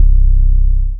808 - Drip.wav